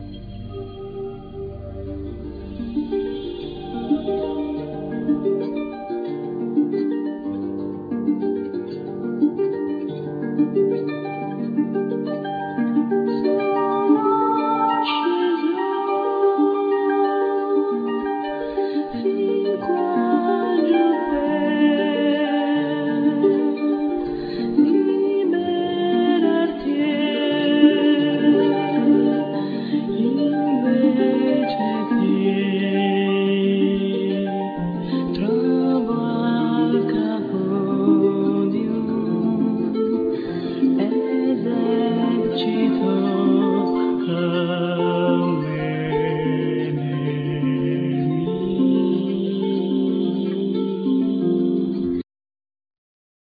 Vocals
Ghironda,Salterio
Viola da Gamba,Ribeca
Flauto dolce,Flauto indiana,Flauto piccolo
Lute,Ud ,Mandlin
Arpa celtica